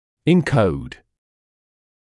[ɪn’kəud][ин’коуд]кодировать; шифровать